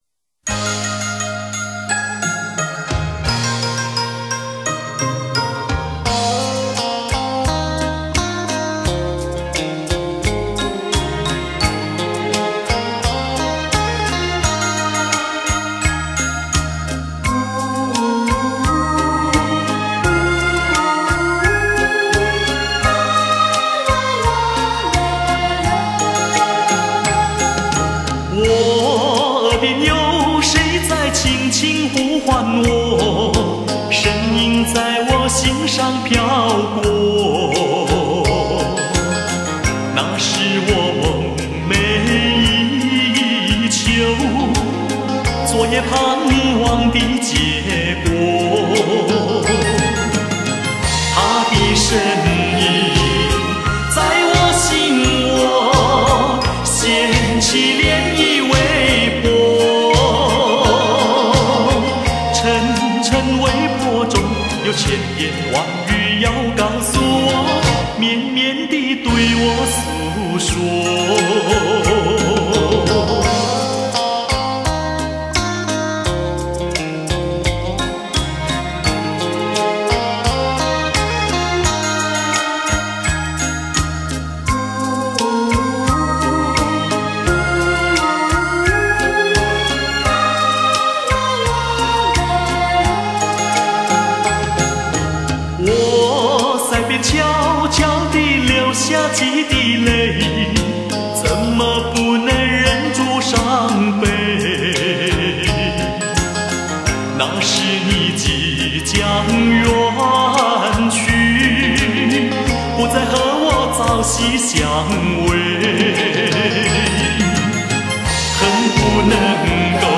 他的嗓音属于雄浑壮伟的类型，演唱技巧老练纯熟，再加上他独家特
持在偏重民族风的路线上，歌路稳健雄浑，大情大性，曲风阳刚气盛